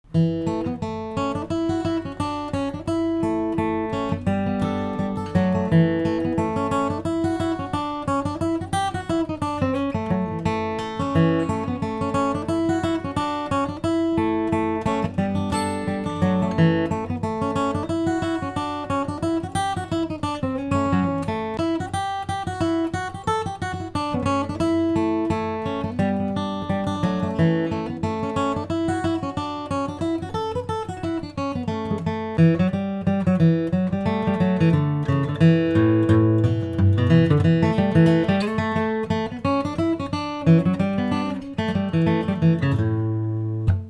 die Melodie ziemlich notengetreu in relativ langsam, so um die 90 - 100 BpM